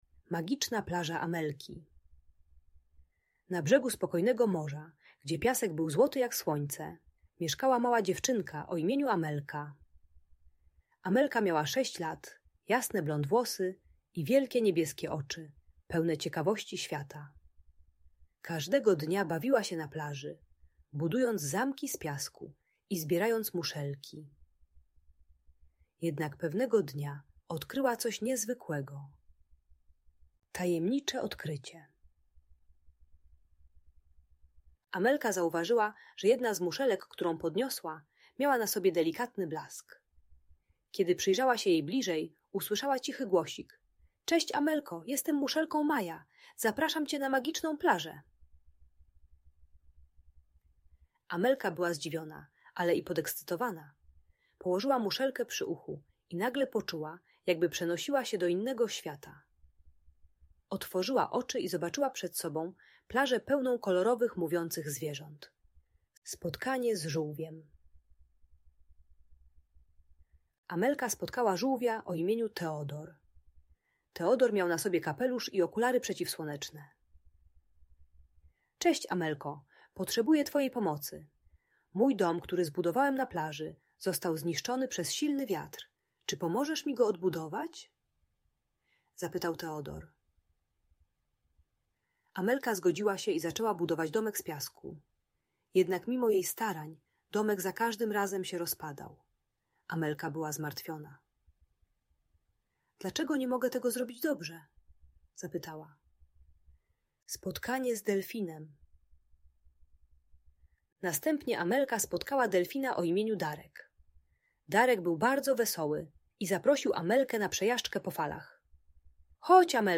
Magiczna Plaża Amelki - Bajkowa historia o przygodach - Audiobajka